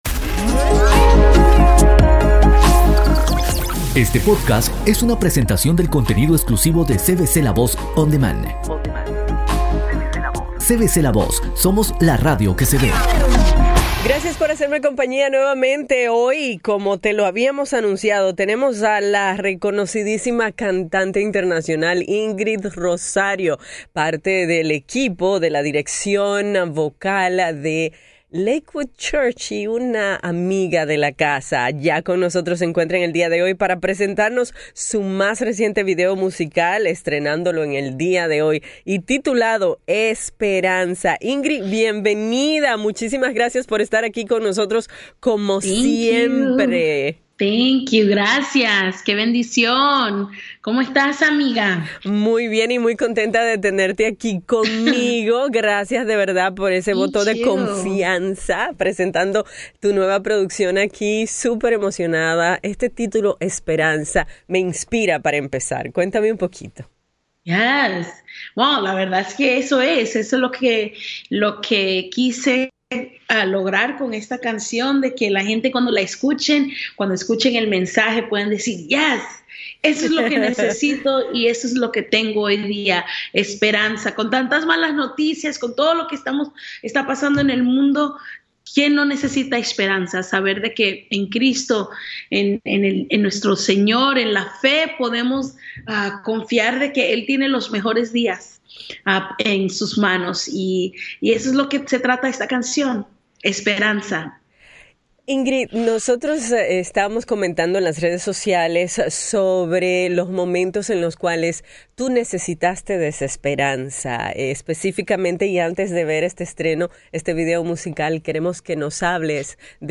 una sincera entrevista